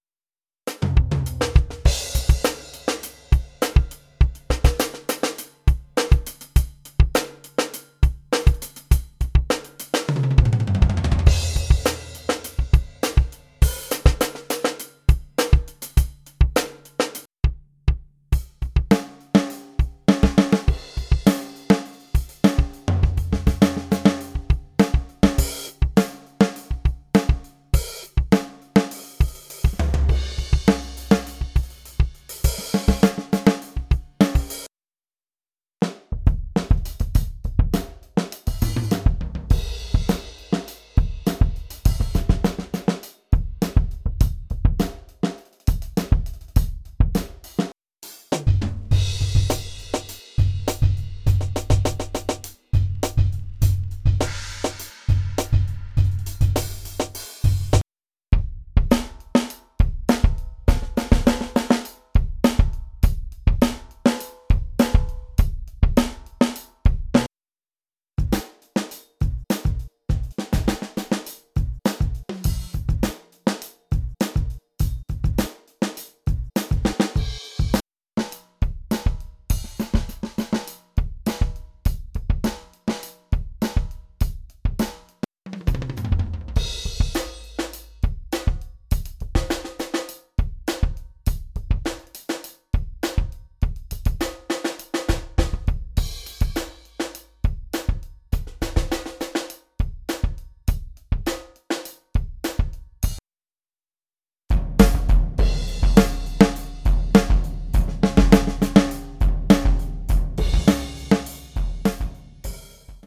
The XL version of Jamstix comes with additional kits for funk and also electronic sounds. There are also mixed kits of acoustic and electronic sounds. The MetalPak also adds nice tight sounds.
This is 'Mark' playing the 'Hip Funk' style. It's unedited so excuse anything he does that needs polishing. I'm changing kits as it plays (which also causes the small interruptions). It starts off with MetalPak+XL Snare then several XL kits and finally a bit of BonzoPak.
funky_kits.mp3